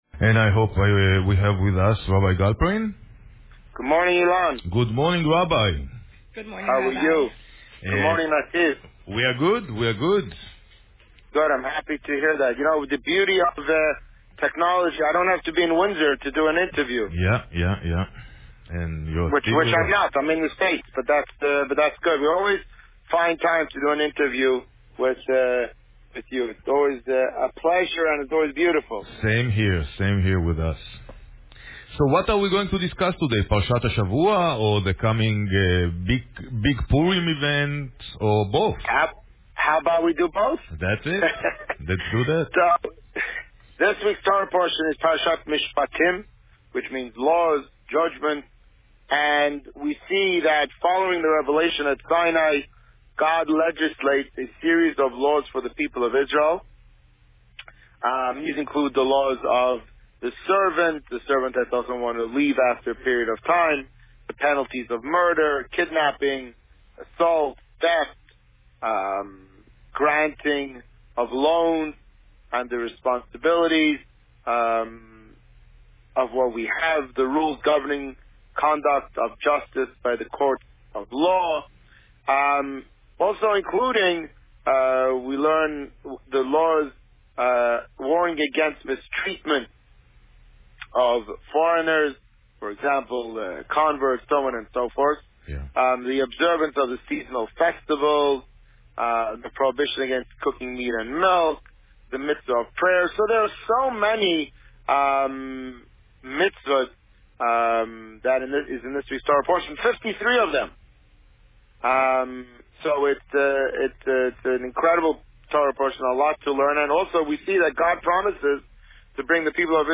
This week, the Rabbi spoke about Parsha Mishpatim and the upcoming Purim party. Listen to the interview here.